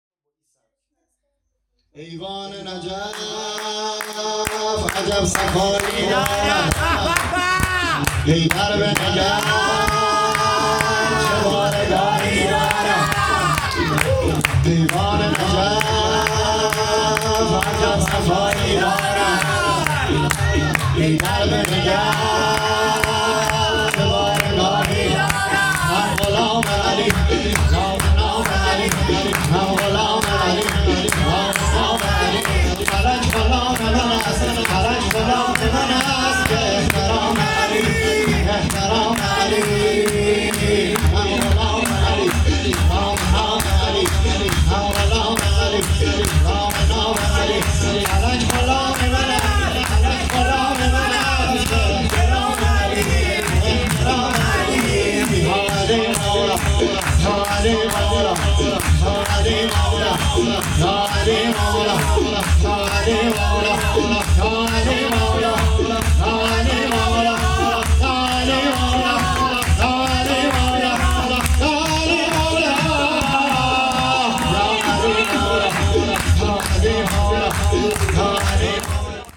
سرودشور
ولادت امام علی(ع)_هیئت میثاق با شهدا